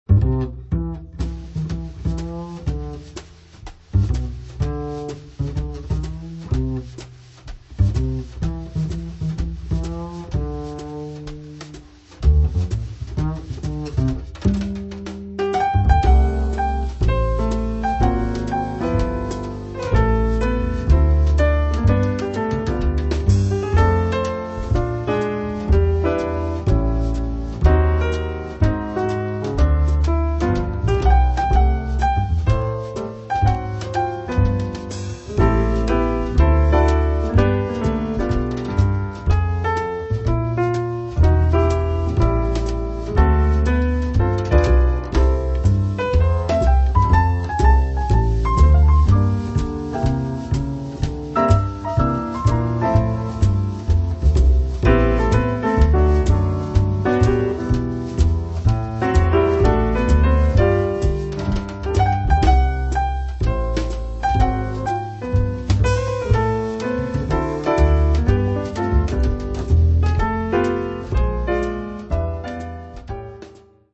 Recorded at: Capital Studios